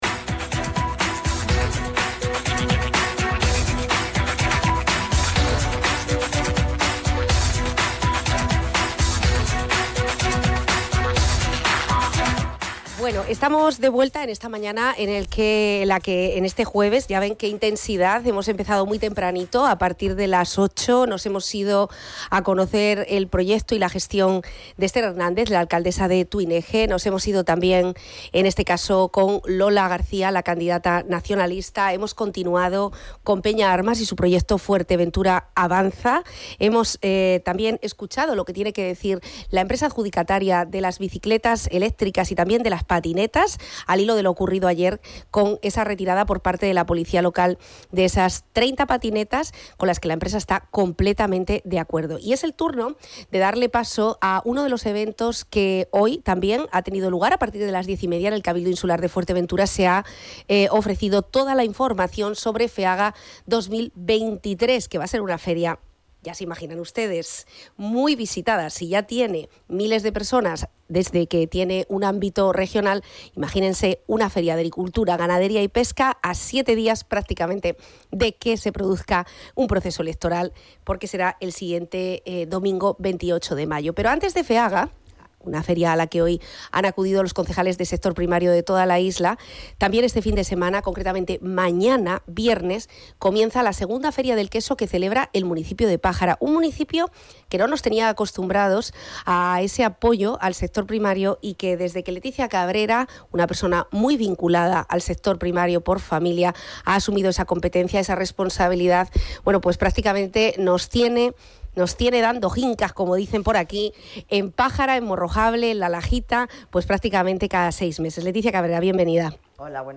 Feria del Queso en la Plaza de Regla en Pájara 28, 29 y 30 de abril. De ello ha hablado la concejala de Sector Primario del Ayuntamiento sureño Leticia Cabrera en El Magacín de Onda Fuerteventura.